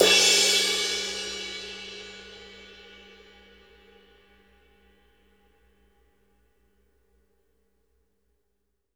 CRASH B   -L.wav